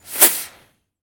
rocketaim.ogg